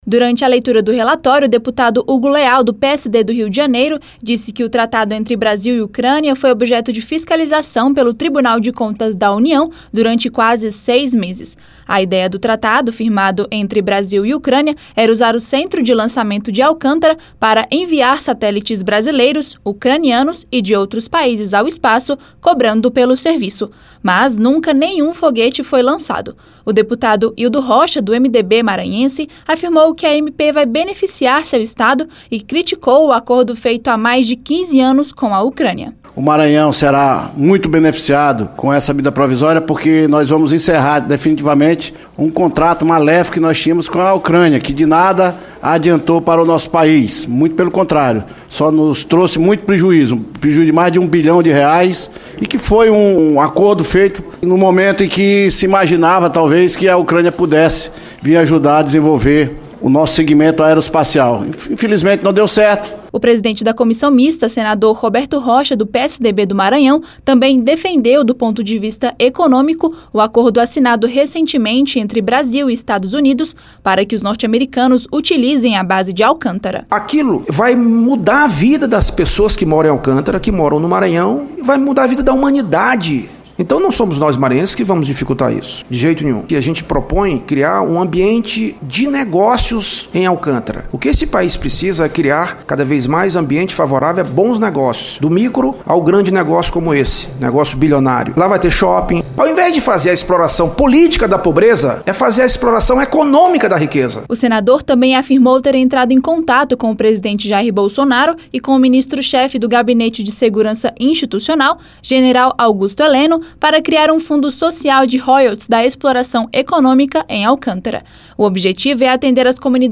O senador Roberto Rocha (PMDB/MA) também defendeu a MP e sinalizou ser favorável ao acordo recentemente assinado entre Brasil e Estados Unidos, que prevê a utilização pelos EUA da base brasileira em Alcântara. Saiba mais na reportagem